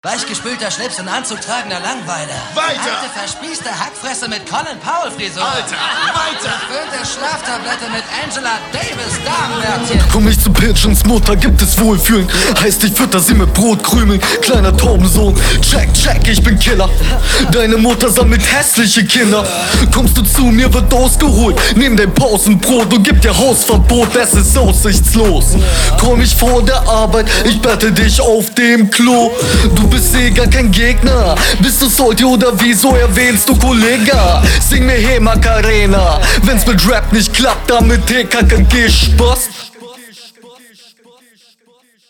Witziges Intro!
Besser verständlich.